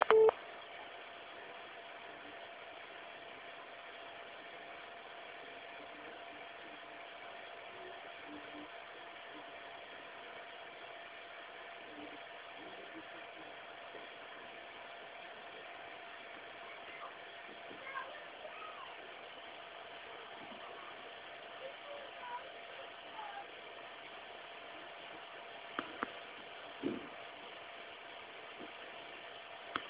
Wiatrak jest dość głośny, co słychać wyraźnie na tym